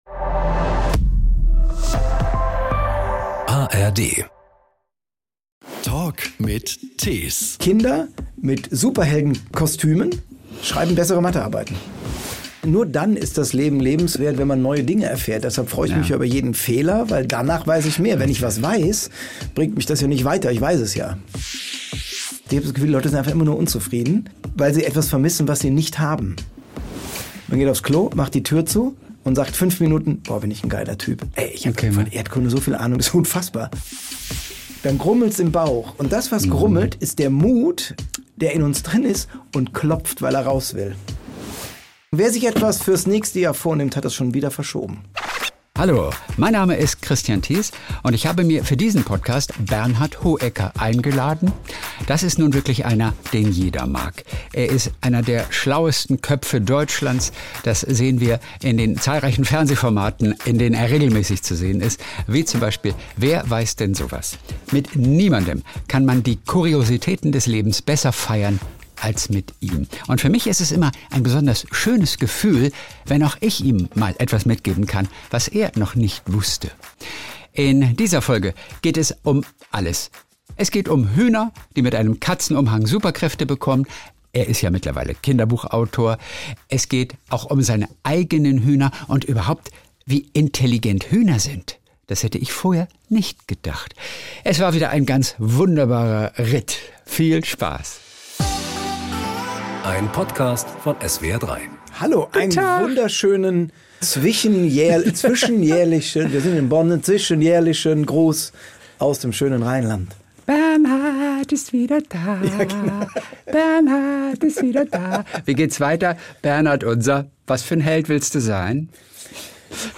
Ein Gespräch mit Bernhard ist immer ein bunter Ritt durch sämtliche Themengebiete.